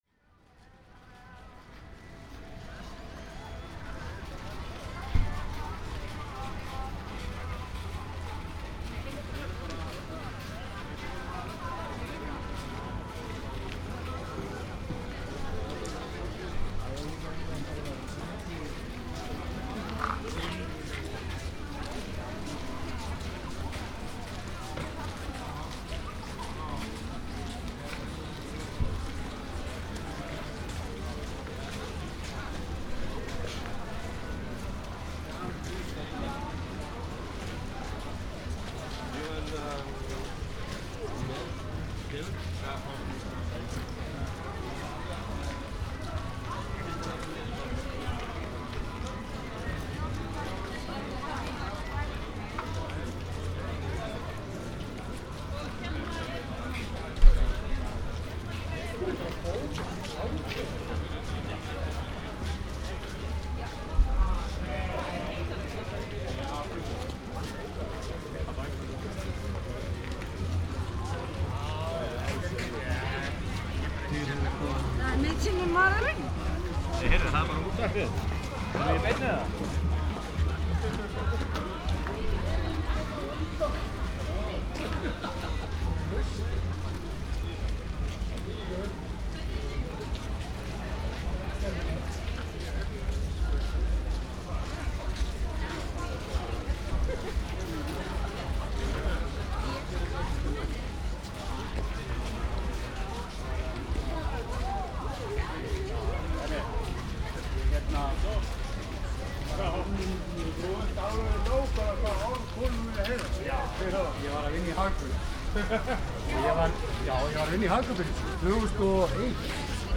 It was a different atmosphere in the city center this year.
This year there were no beautiful singing voices in the crowd as so often before. However, music comes from some street stalls and bars. On the ice skating rink at Hallærisplan (Ingólfstorg square), music was played loud during the skating dance. After total 1Km long walk the recording ends rather quietly in a bar where I bought a beer. In this recording I am using the same ultralight stereo microphone as I did in 2017.
It reduced the audible phase error between the channels which was clearly audible in the 2017 recording.